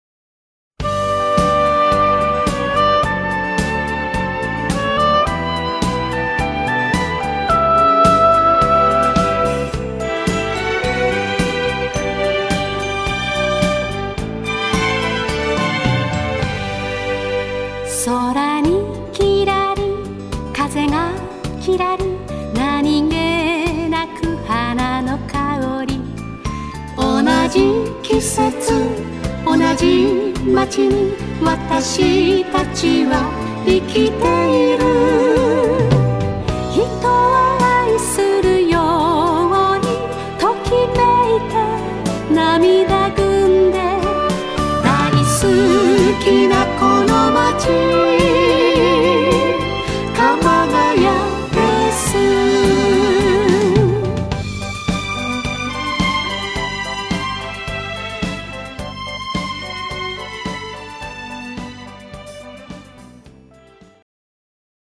（1番のみ）